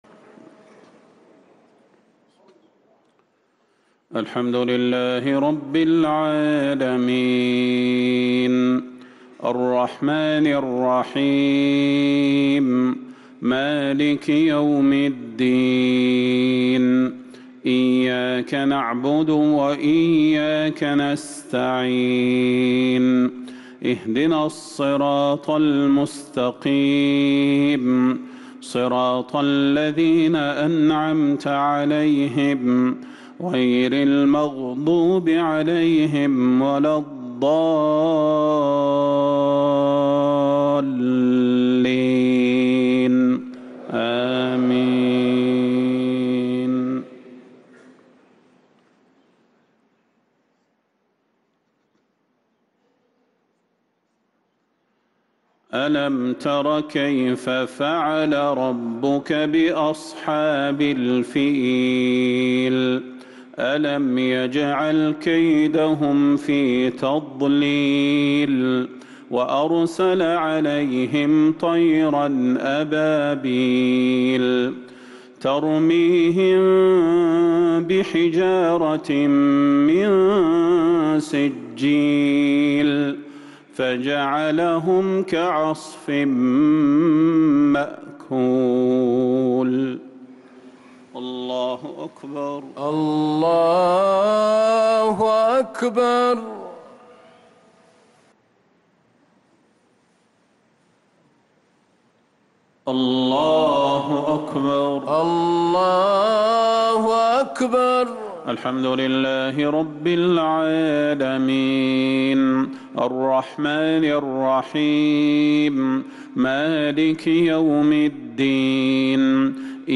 صلاة المغرب للقارئ صلاح البدير 26 ربيع الآخر 1445 هـ
تِلَاوَات الْحَرَمَيْن .